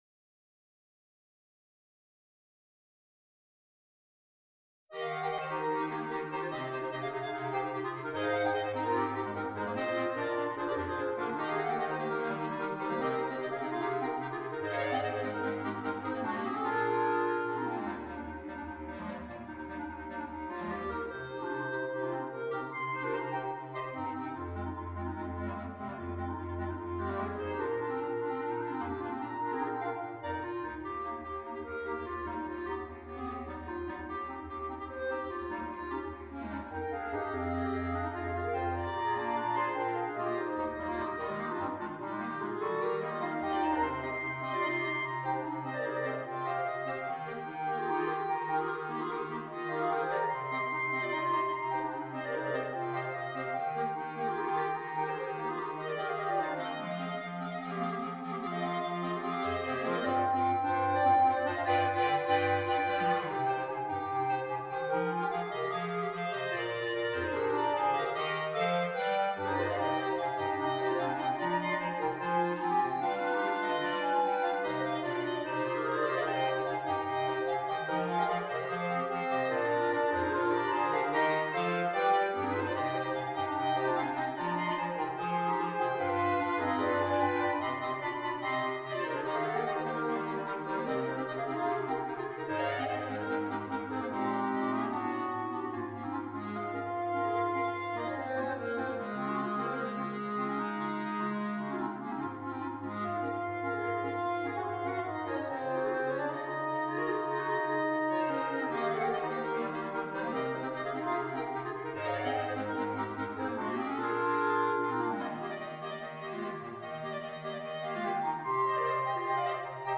单簧管五重奏
风格： 动漫